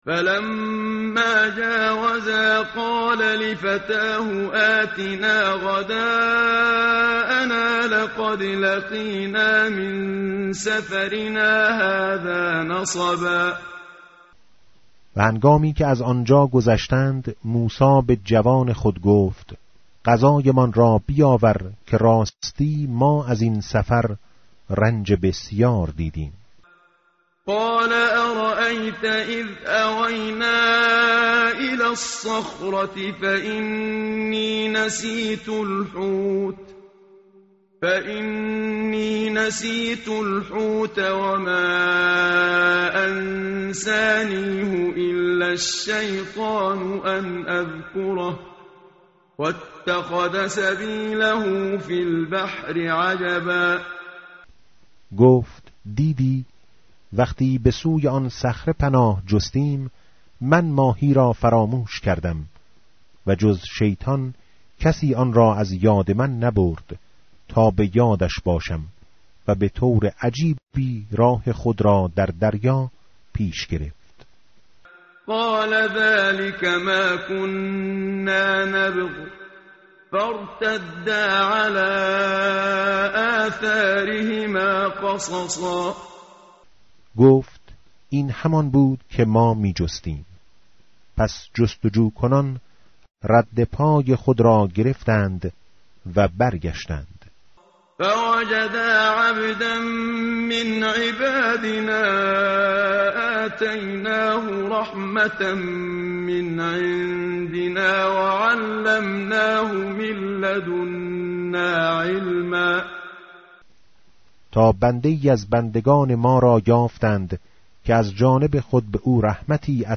tartil_menshavi va tarjome_Page_301.mp3